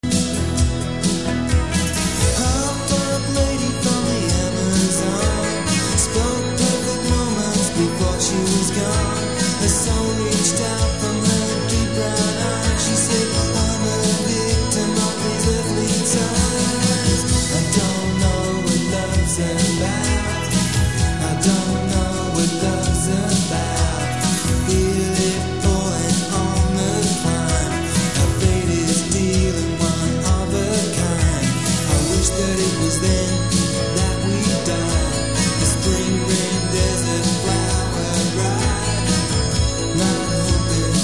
Recorded at La Maison and Trackdown studios.